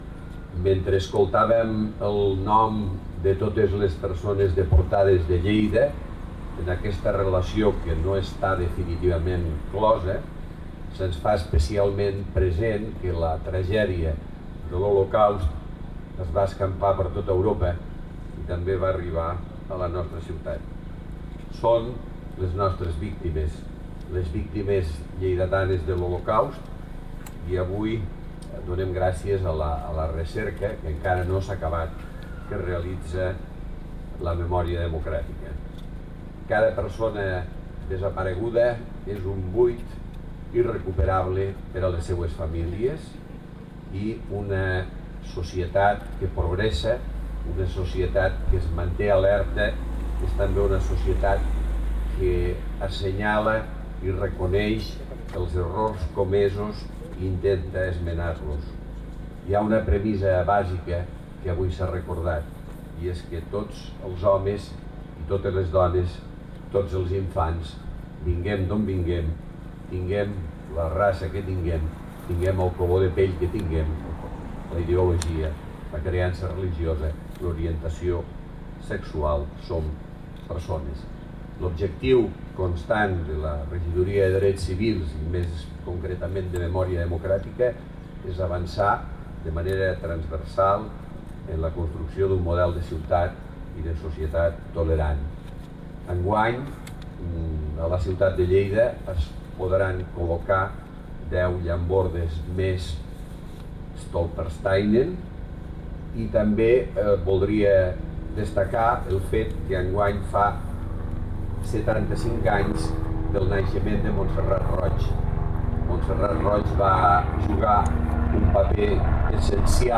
tall-de-veu-del-paer-en-cap-miquel-pueyo